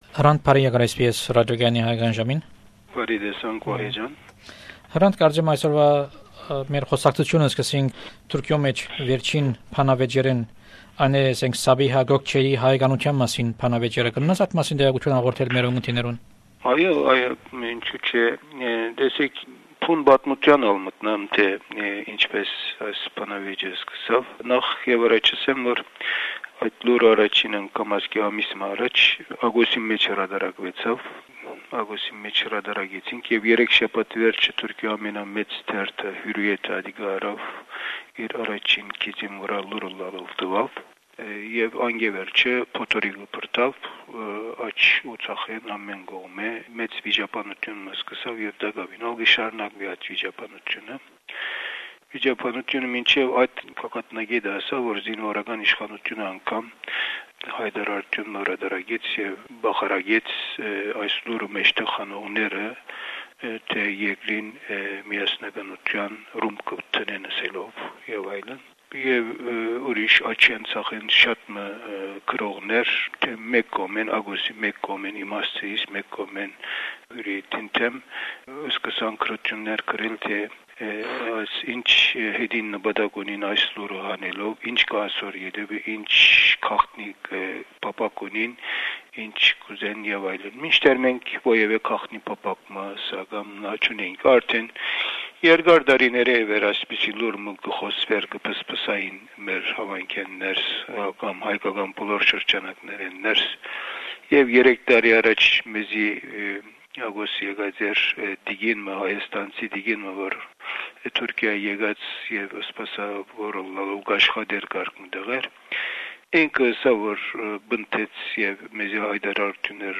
This is the seventh interview with the editor in chief of Agos newspaper in Istanbul, Hrant Dink in February 2004.